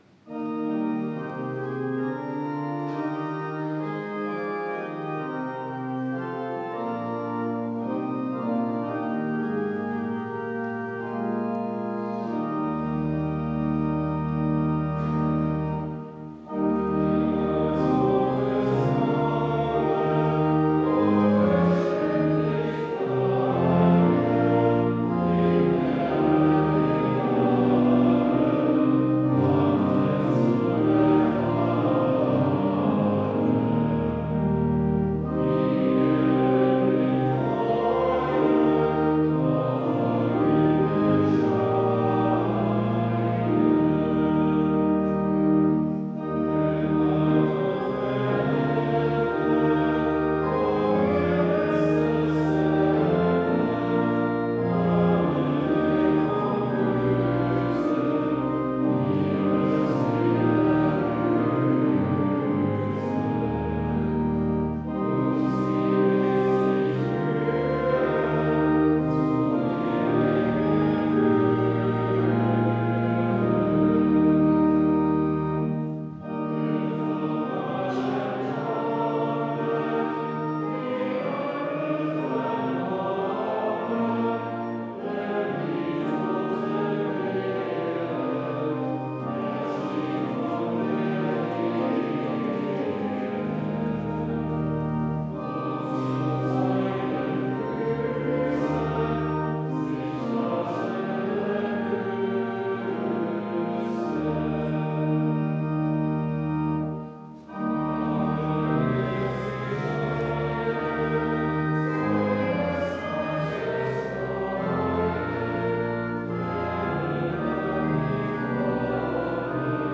Audiomitschnitt unseres Gottesdienstes vom 2. Sonntag nach Epipanias 2026.